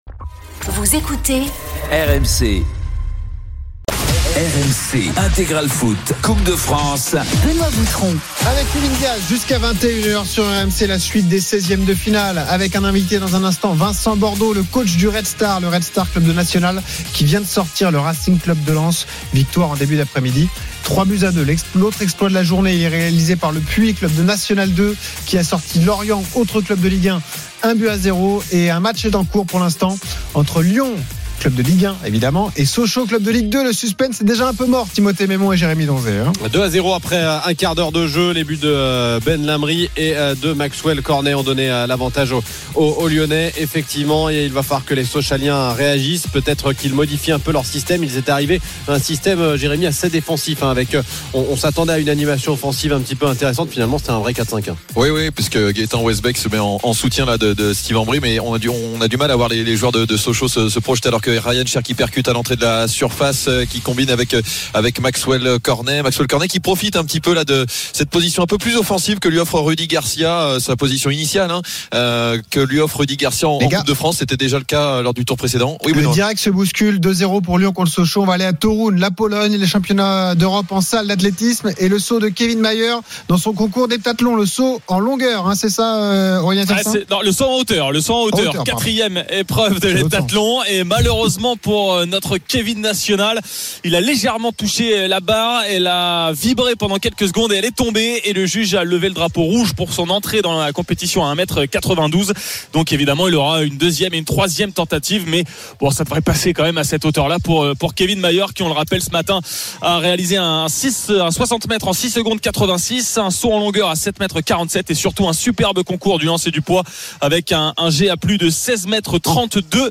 Live, résultats, interviews, analyses, ...